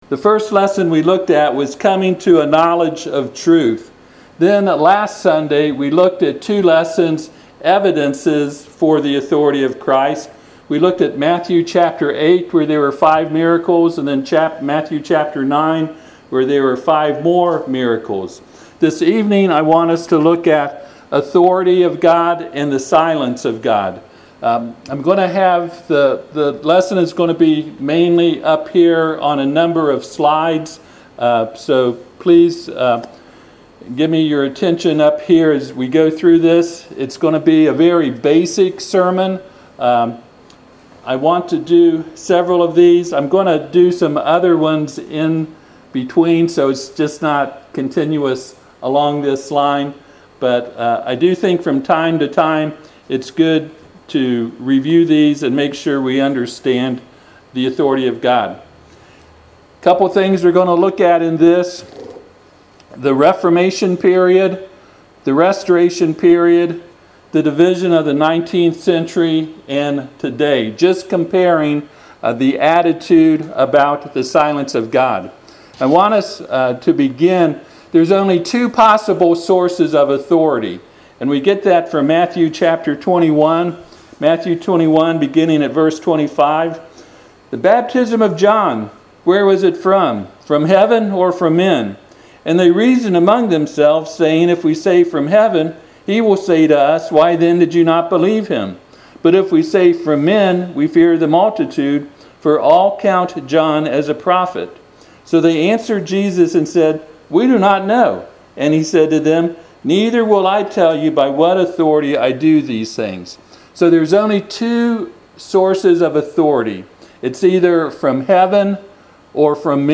Passage: Numbers 15:32-36 Service Type: Sunday PM Topics